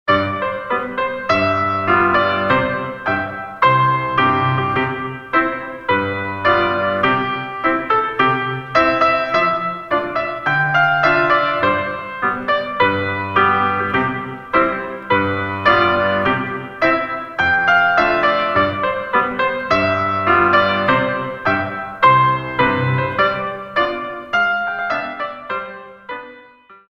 In 2